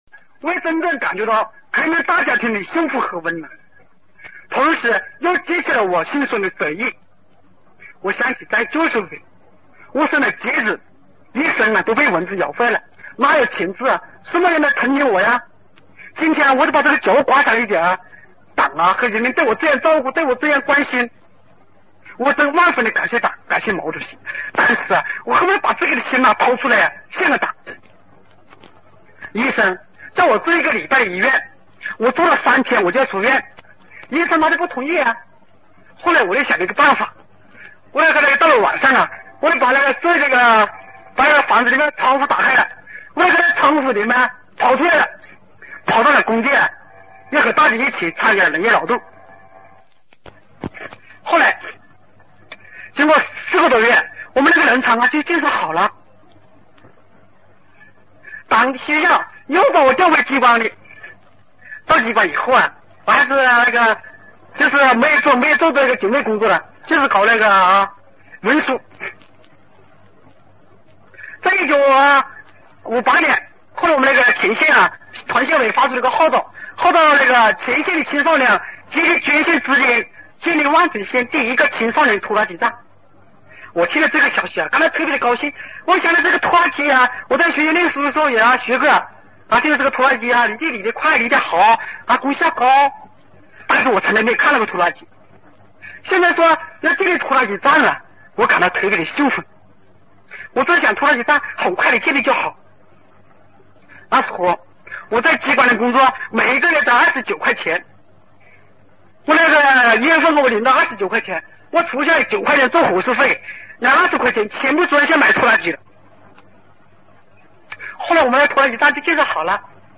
[14/8/2012]【雷锋逝世50周年纪念】雷锋讲话实况录音 激动社区，陪你一起慢慢变老！